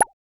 Tech Bubble Pop Notification.wav